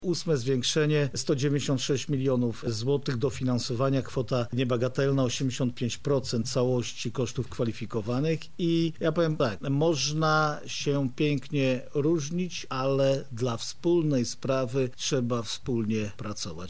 O całkowitym dofinansowaniu, jakie udało się uzyskać z Funduszy Europejskich, mówi Jarosław Stawiarski, Marszałek Województwa Lubelskiego :